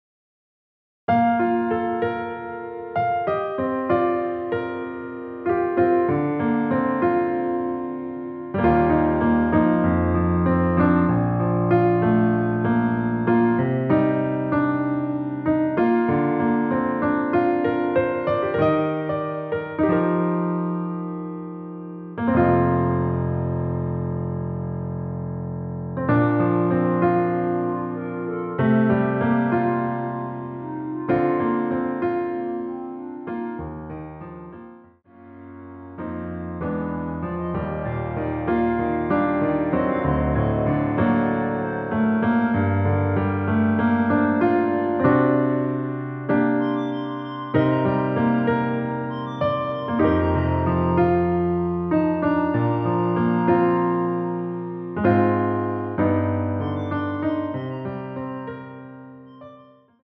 원키 멜로디 포함된 MR입니다.
Bb
앞부분30초, 뒷부분30초씩 편집해서 올려 드리고 있습니다.
중간에 음이 끈어지고 다시 나오는 이유는